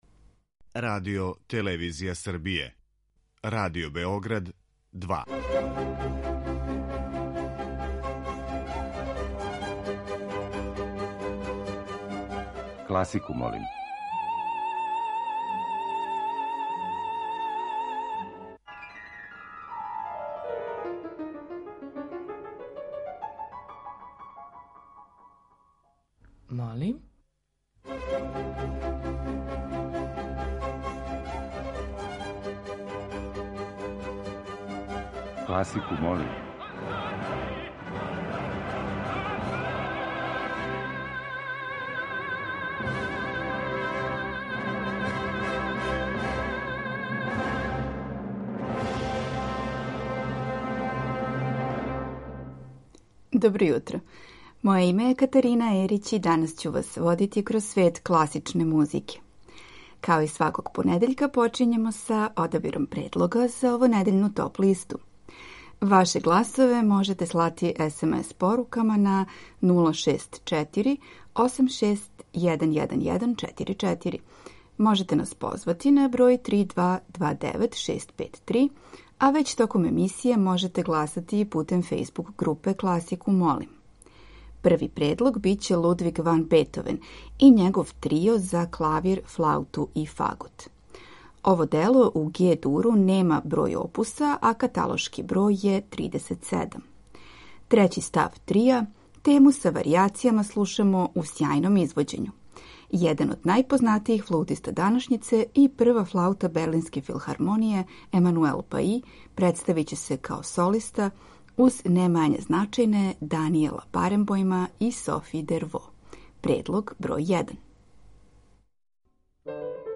Емисија Класику, молим ове седмице води вас у различите епохе и стилове, а представља и неуобичајене оркестрације композиција, какав је, рецимо, Прелудијум за оргуље и оркестар.